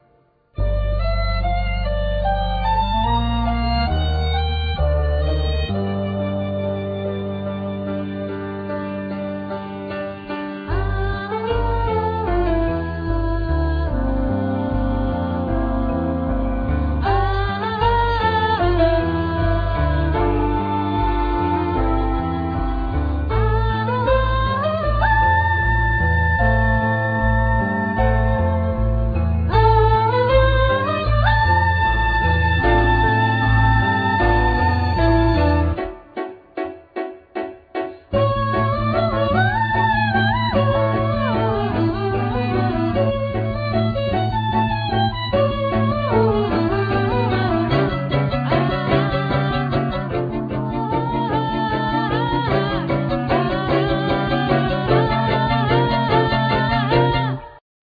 Vocal,Piano
Guitar,Vocal,Bass
Violin
Clarinet
Percussions
El.guitar